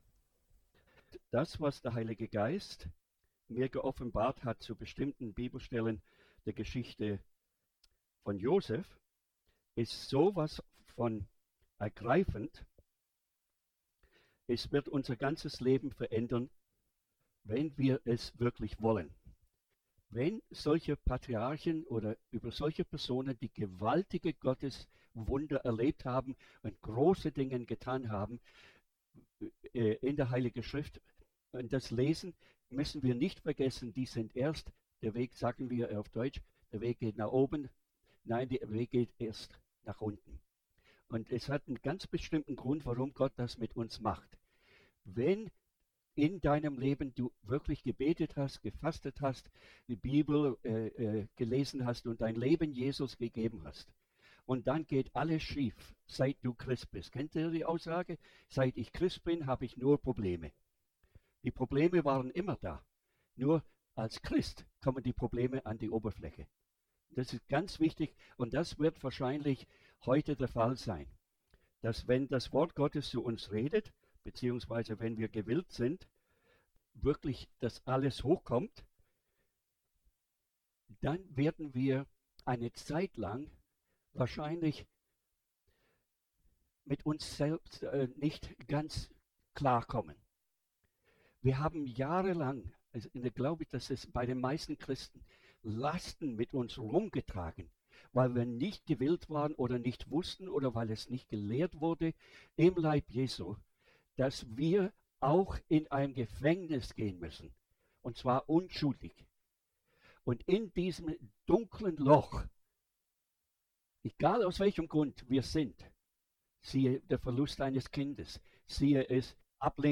Seminaraufnahme